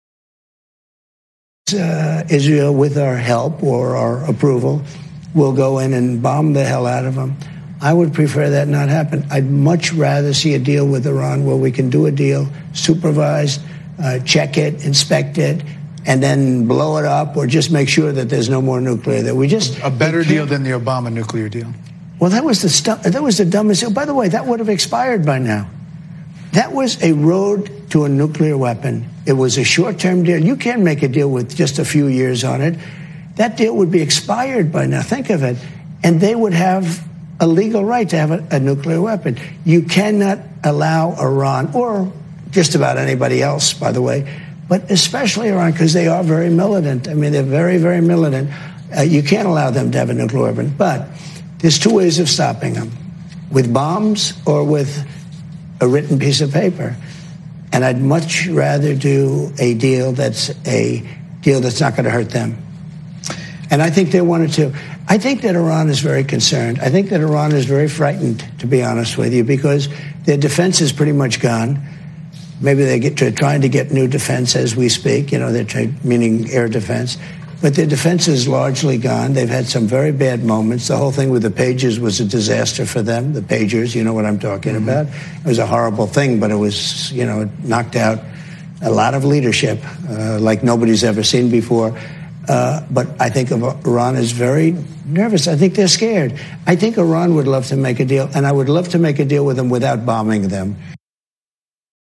در مصاحبه با فاکس نیوز